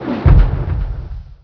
whirligigFire.wav